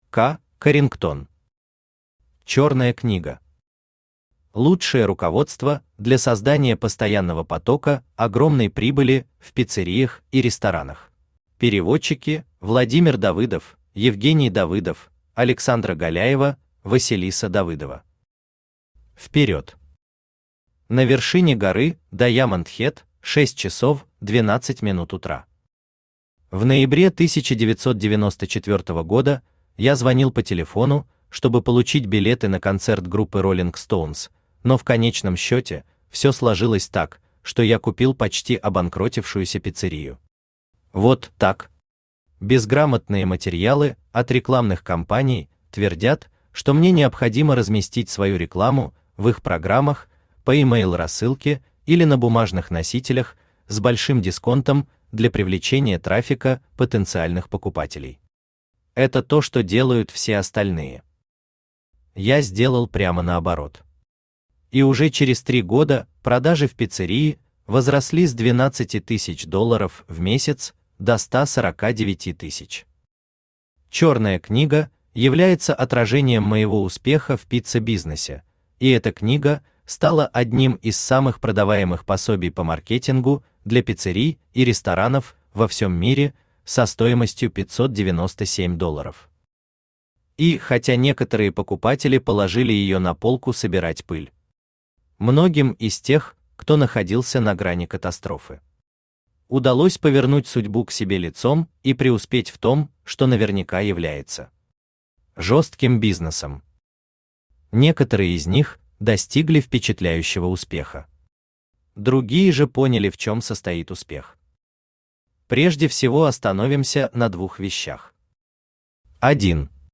Аудиокнига Черная книга | Библиотека аудиокниг
Aудиокнига Черная книга Автор Кэмерон Карингтон Читает аудиокнигу Авточтец ЛитРес.